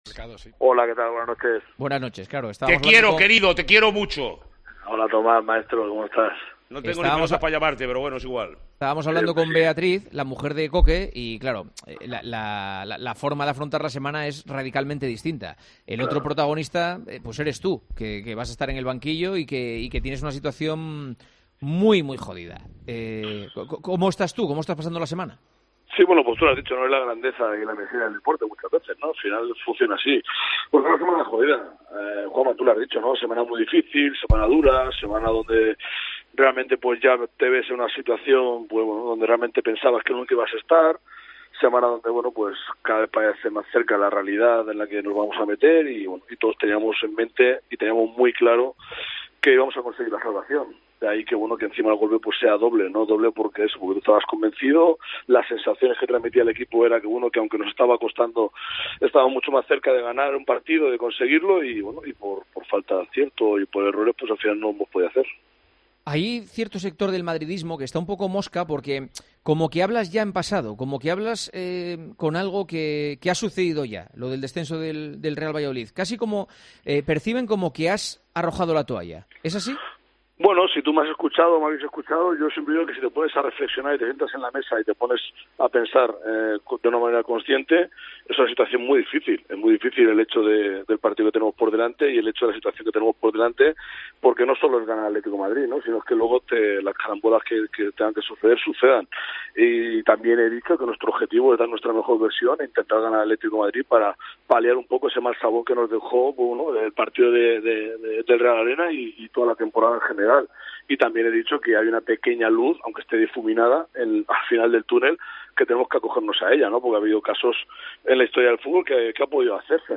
El entrenador del Real Valladolid ha pasado este jueves por los micrófonos de El Partidazo de COPE para hablar de cómo afronta su equipo el partido de la última jornada ante el Atlético de Madrid en el que los rojiblancos pueden coronarse como campeones de LaLiga Santander y su equipo descensar a LaLiga SmartBank.